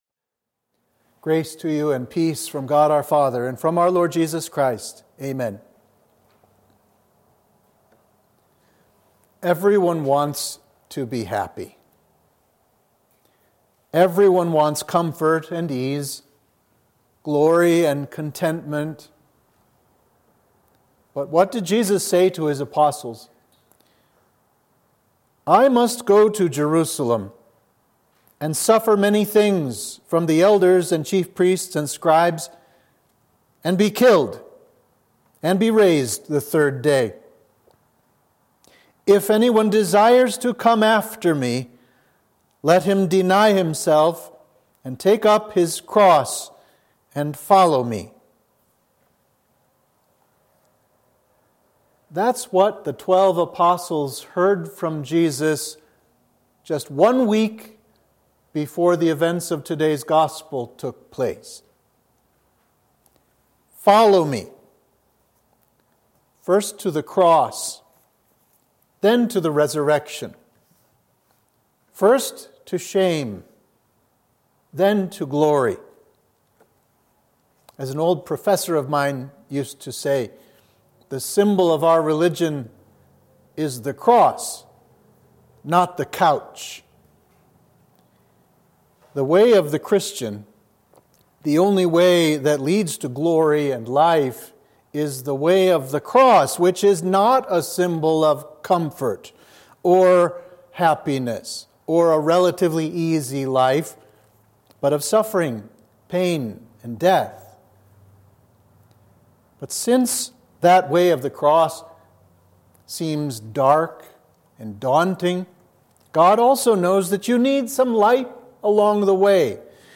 Sermon for the Transfiguration of Our Lord